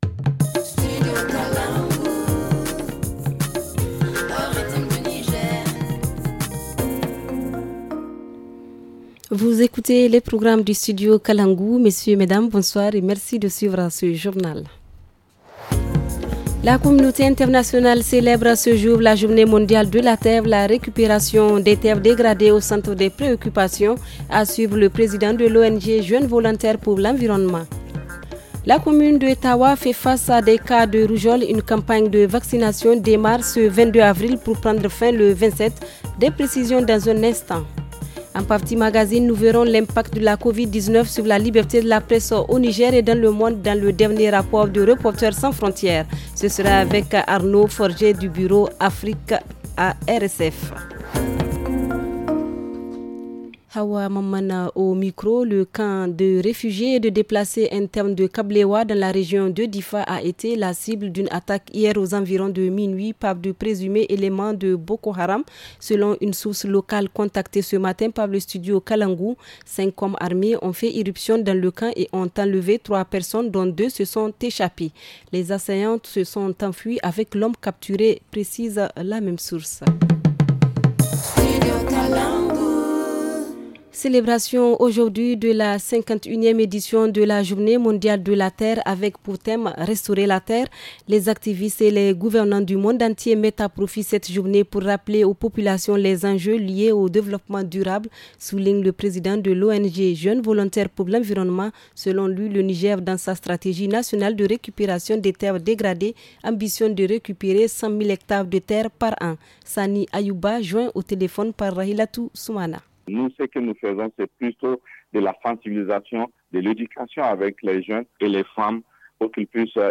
Le journal du 22 avril 2021 - Studio Kalangou - Au rythme du Niger